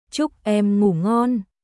Chúc em ngủ ngonチュック・エム・グー・ゴンおやすみ（恋人・年下に対して）